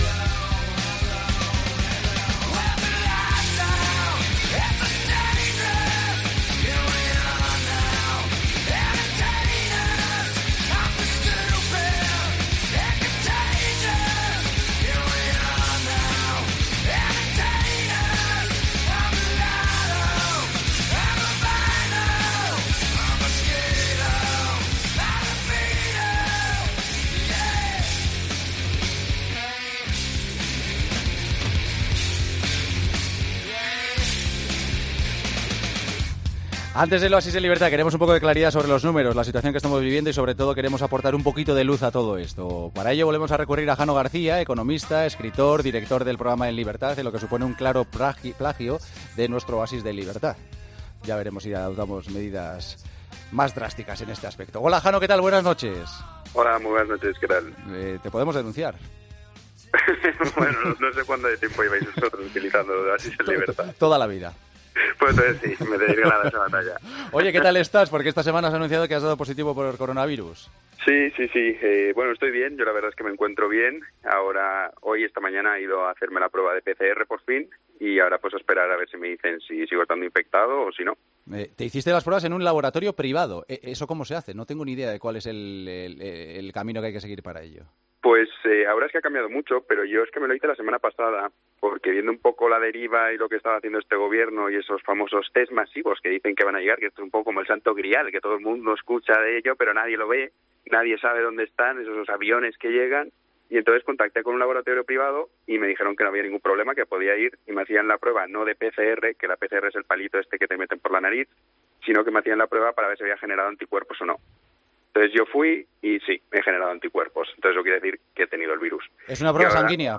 AUDIO: Hablamos con el escrito y economista sobre la época que estamos viviendo.